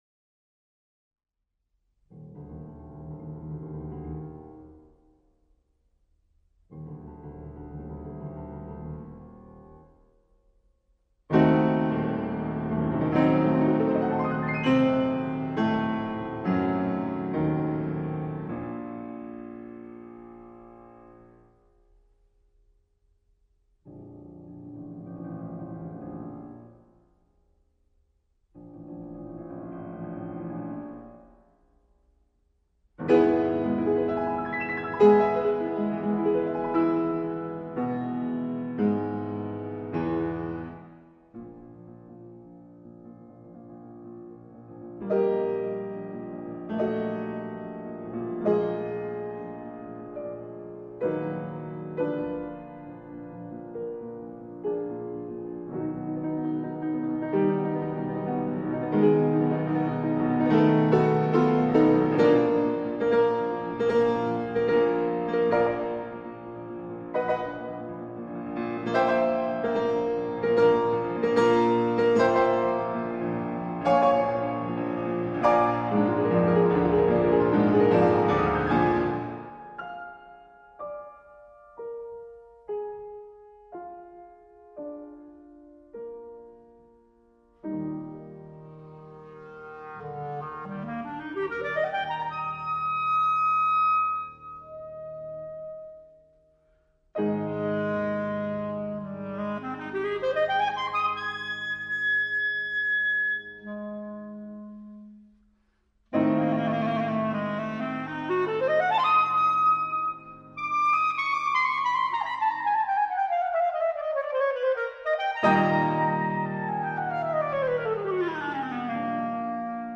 Variazioni per clarinetto e pianoforte